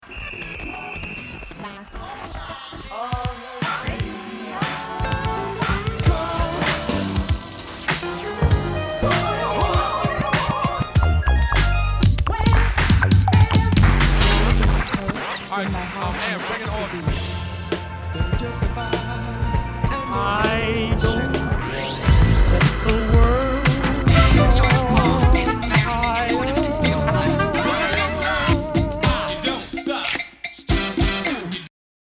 So I dug out the vinyl and made
It's not highly listenable but it's quite 'me'.
There are 20 tracks in there.